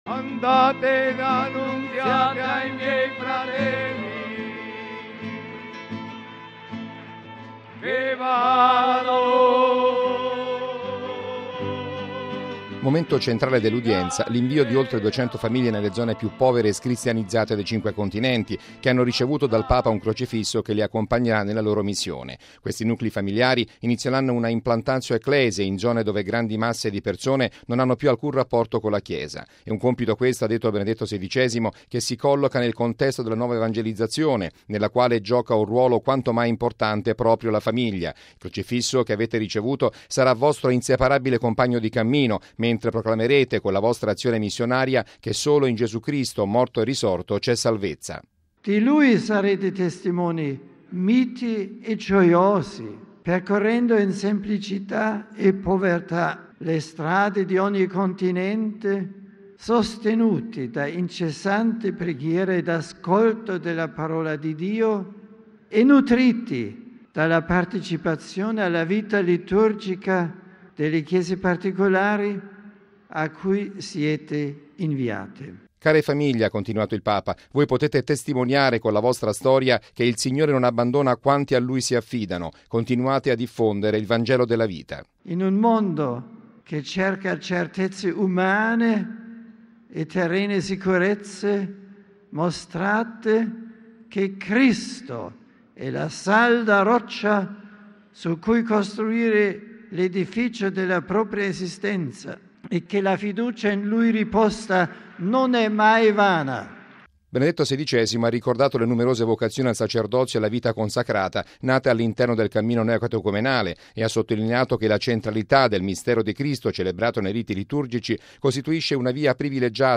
(12 gennaio 2006 - RV) Benedetto XVI ha inviato questa mattina nell’Aula Paolo VI duecento famiglie in missione in alcune delle zone più scristianizzate del mondo nel corso della prima udienza concessa dal Papa al Cammino Neocatecumenale.
Il servizio